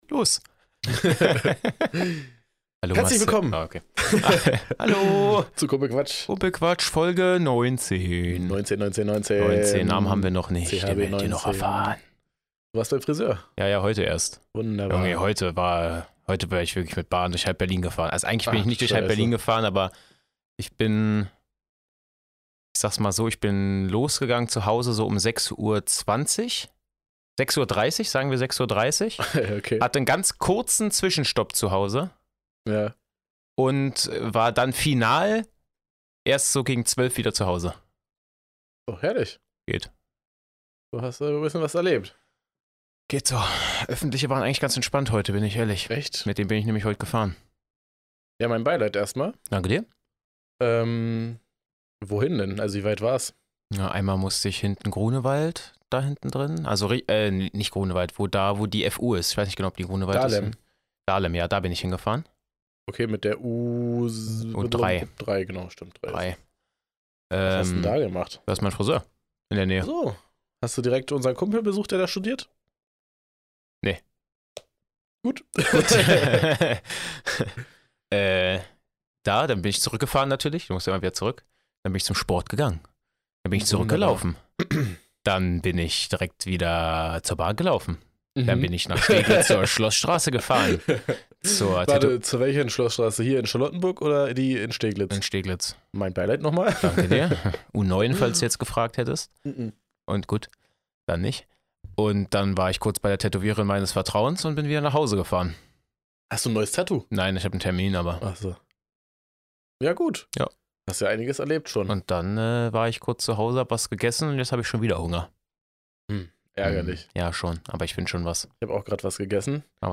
Eine hitzige Diskussionsfolge.